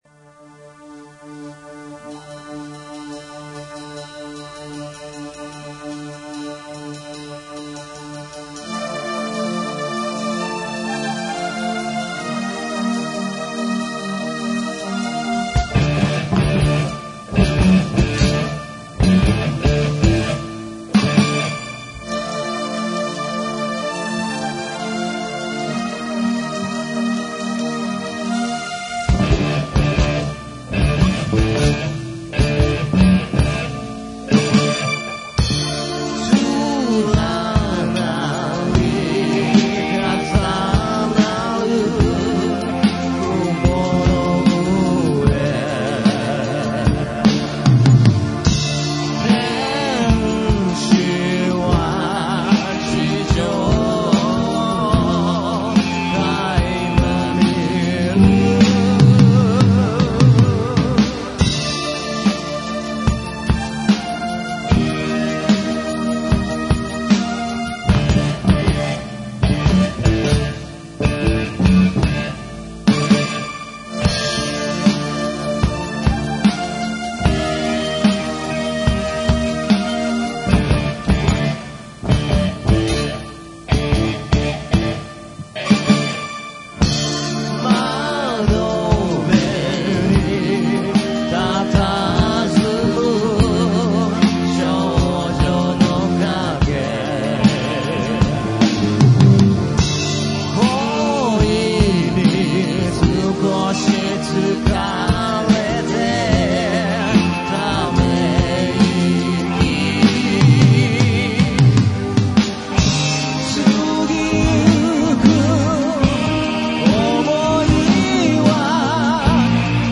(コーラス・デモ)
普通に並行ハーモニーを加えるだけでなく、主旋律とは全くメロディの異なるセカンドラインを考案。